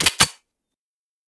select-pistol-1.ogg